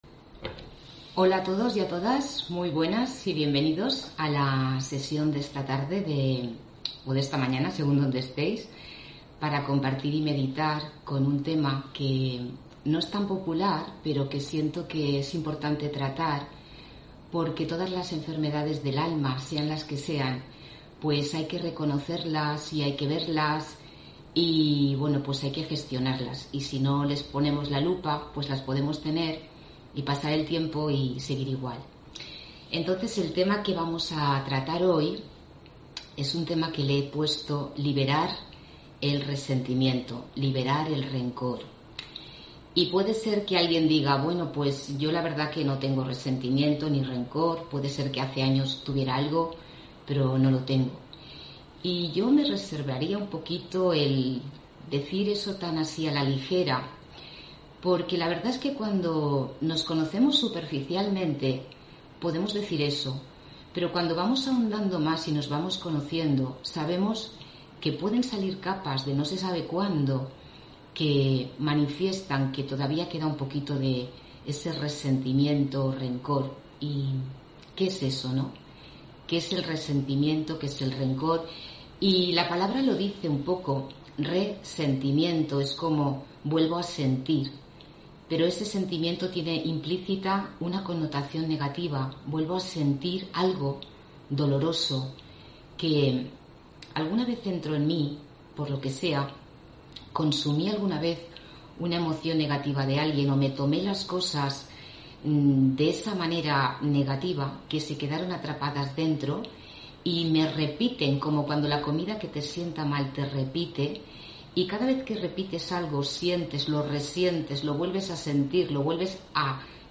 Meditación Raja Yoga y charla: Liberar el resentimiento (10 Julio 2021) On-line desde Valencia